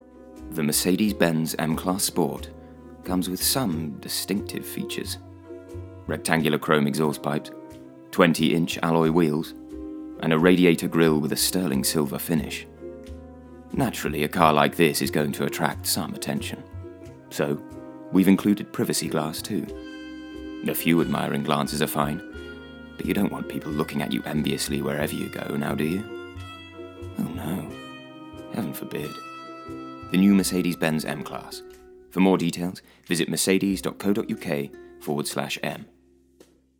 • Male
Showing: Commerical Clips
Mercedes. Smooth, Stylish, Light Hearted, Comedy